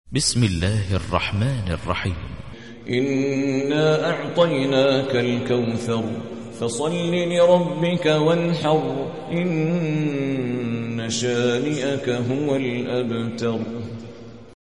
108. سورة الكوثر / القارئ